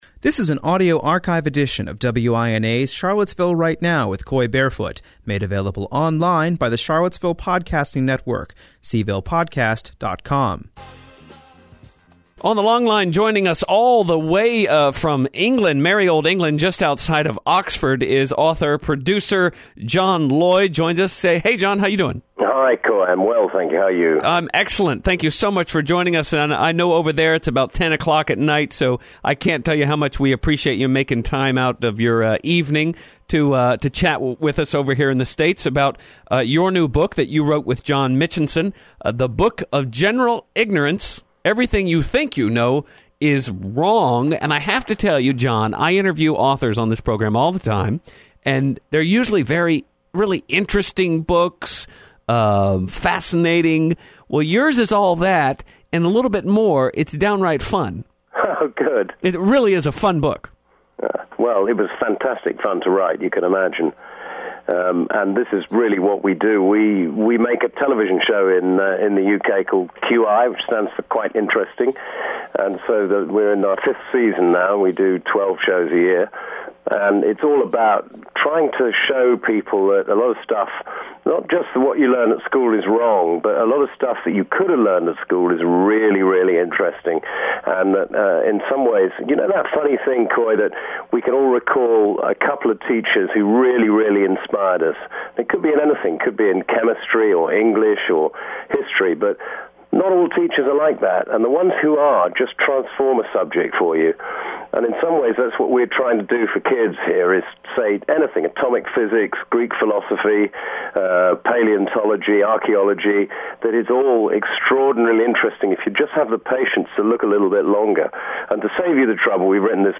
Author and producer John Lloyd phones in from England to discuss The Book of General Ignorance: Everything You Think You Know Is Wrong .